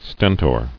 [sten·tor]